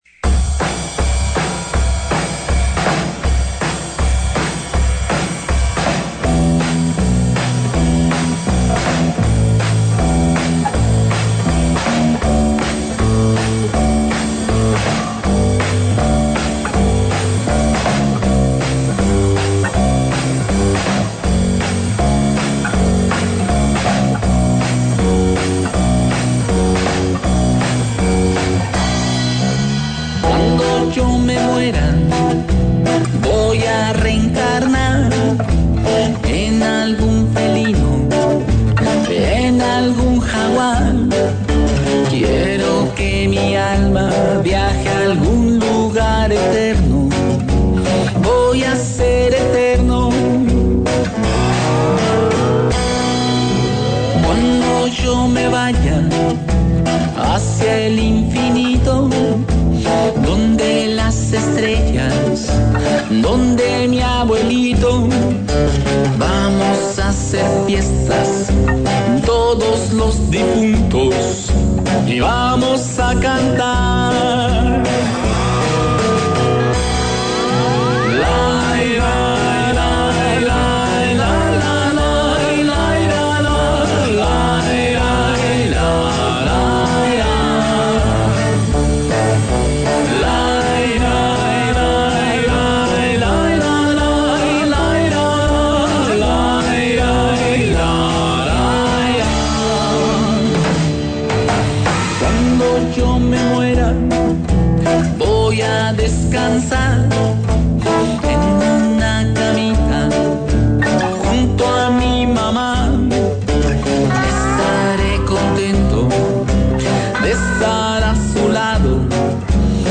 La calaverita en México es de dulce y huele a calabaza, así que pasa, rellénate los tímpanos de canciones divertidas y afila tu imaginación. Tendrás también la ocasión de acompañar al sepelio de un señor pato.